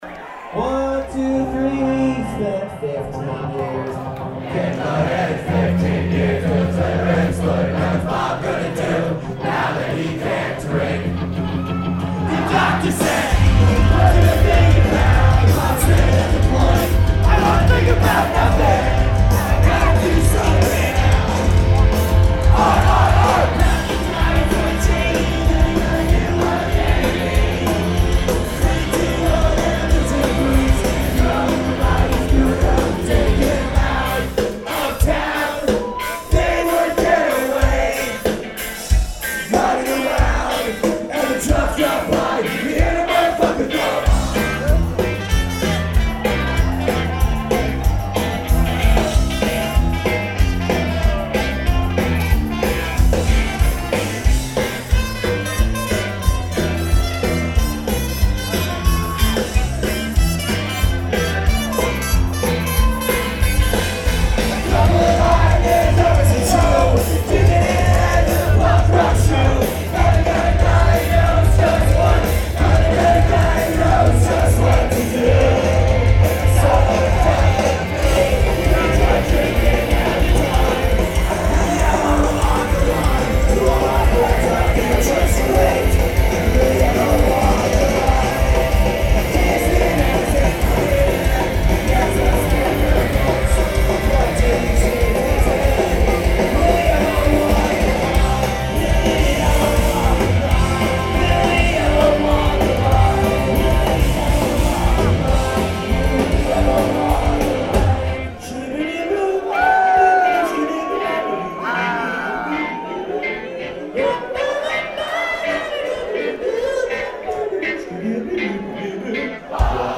brat-punk band